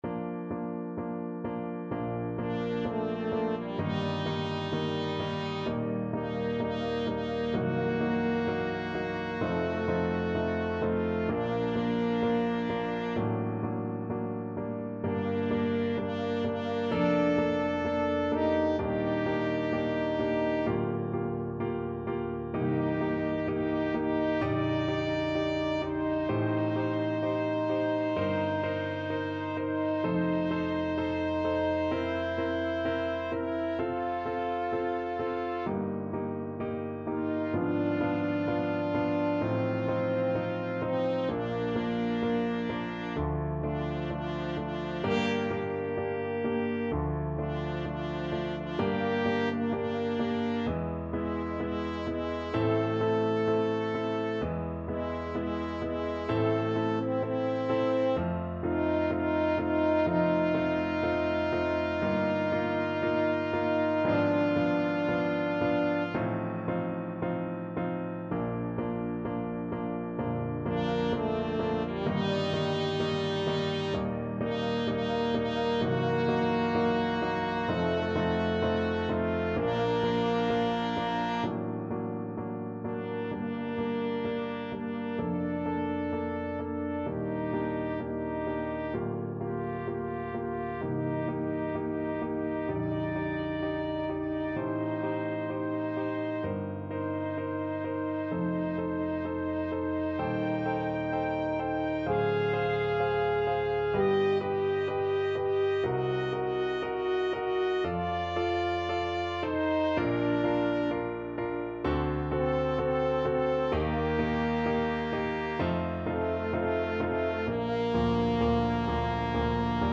TrumpetFrench HornPiano
Nicht zu schnell = 64 Nicht zu schnell